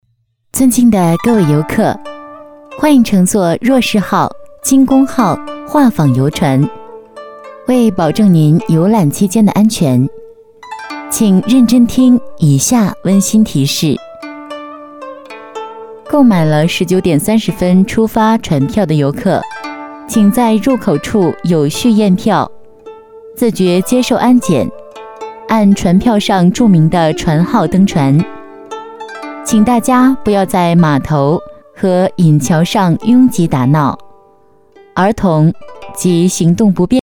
标签： 亲切
配音风格： 亲切 温情 激情 力度 清新 讲述